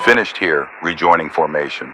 Added .ogg files for new radio messages
Radio-pilotWingmanRejoinTaskComplete4.ogg